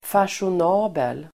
Ladda ner uttalet
Uttal: [fasjon'a:bel]